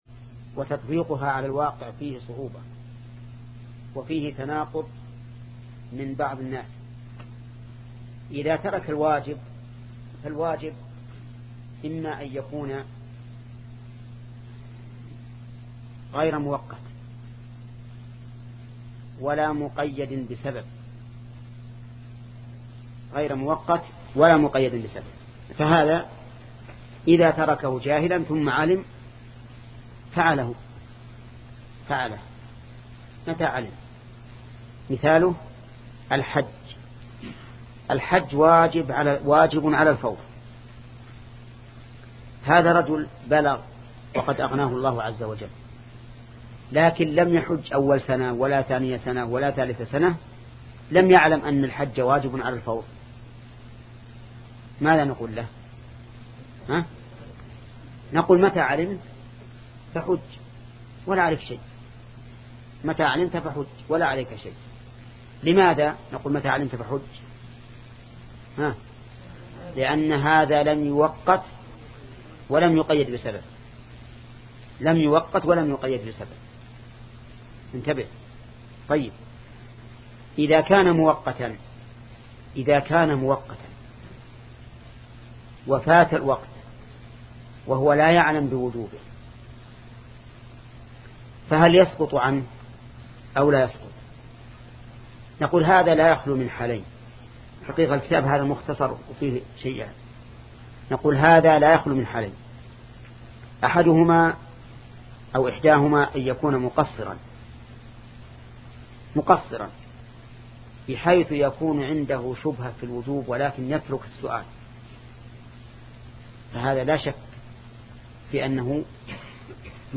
شبكة المعرفة الإسلامية | الدروس | الأصول من علم الأصول 7 |محمد بن صالح العثيمين